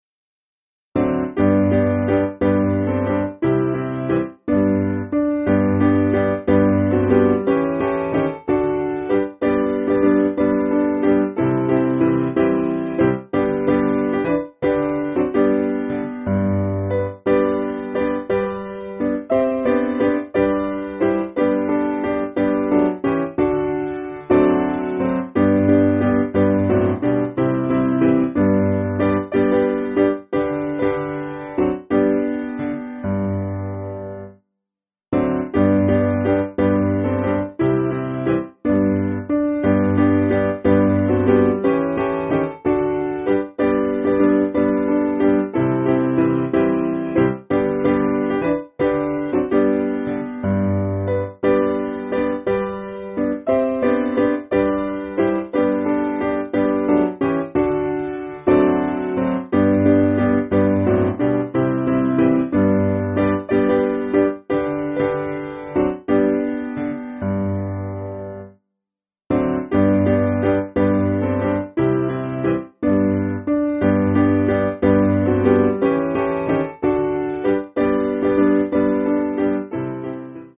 Simple Piano
(CM)   3/G